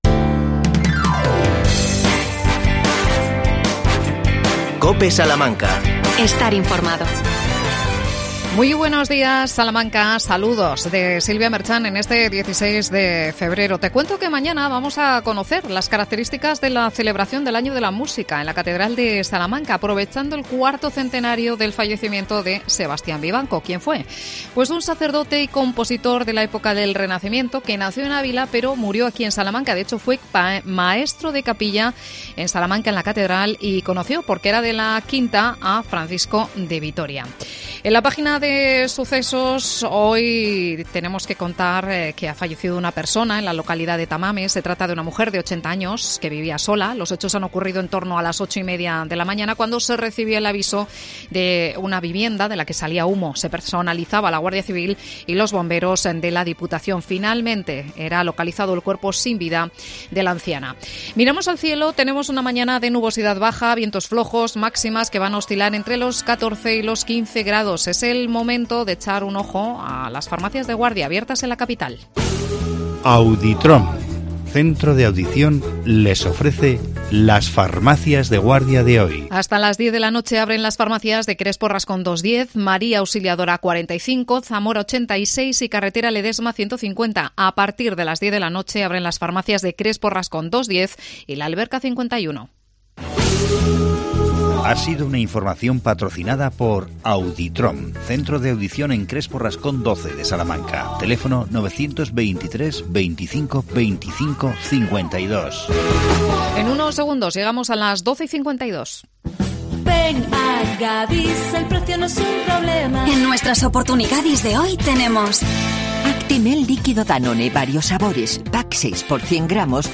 AUDIO: Entrevista al alcalde de Aldeatejada Enrique García. Nuevo paso de peatones.